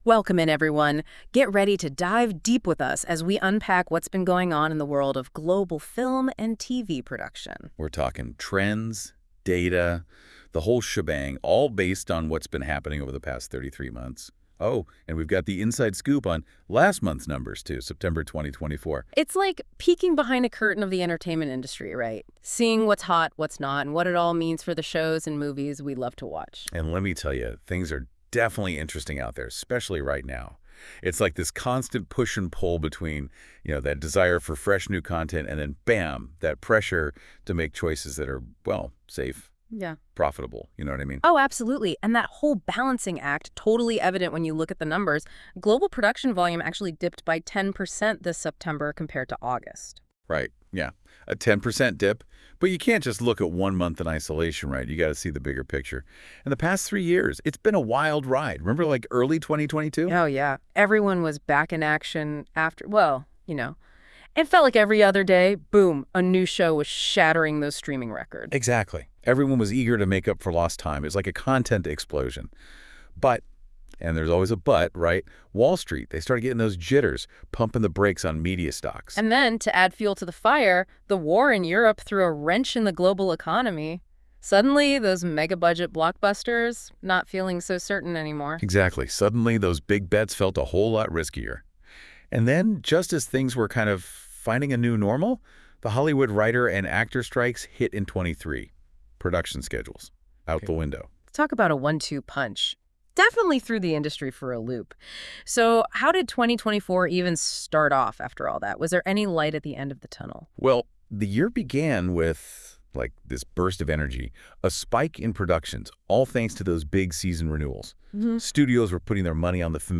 The audio podcast was generated with Deep Dive and reviewed by our team.